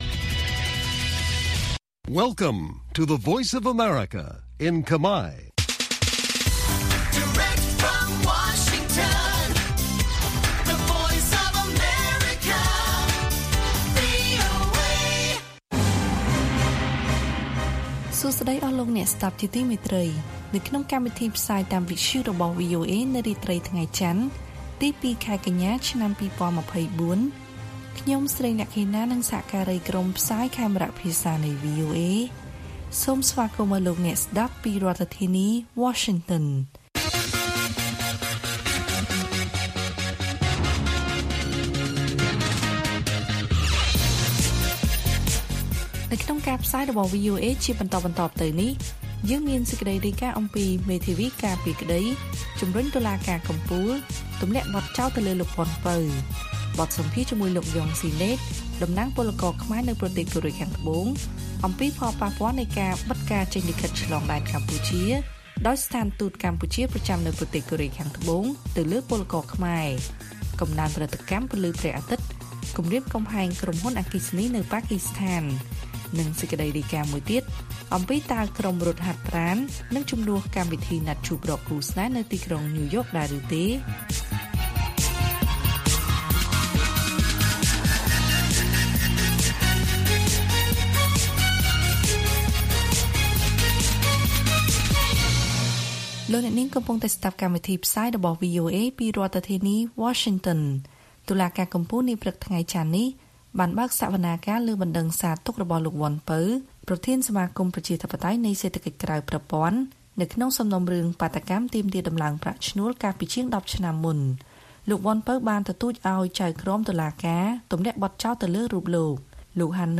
ព័ត៌មានពេលរាត្រី ២ កញ្ញា៖ មេធាវីការពារក្តីជំរុញតុលាការកំពូលទម្លាក់បទចោទលើ លោក វន់ ពៅ